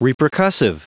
Prononciation du mot repercussive en anglais (fichier audio)
Prononciation du mot : repercussive